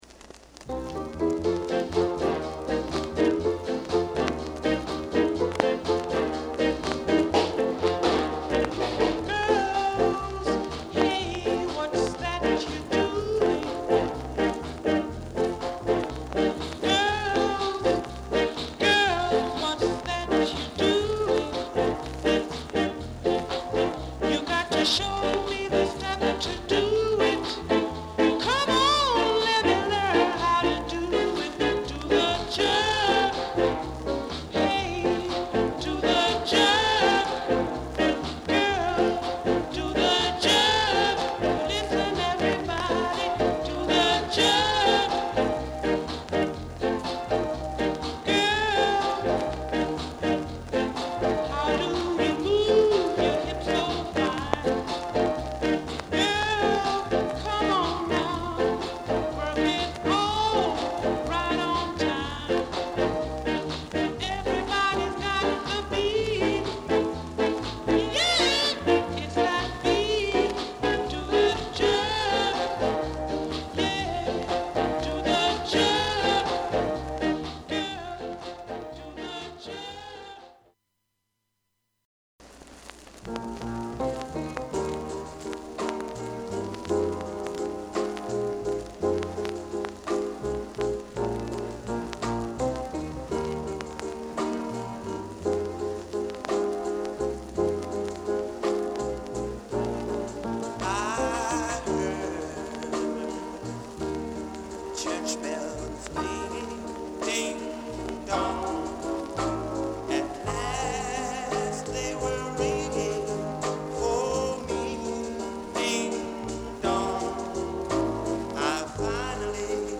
Genre: Ska
軽やかでソウルフルなヴォーカルが持ち味。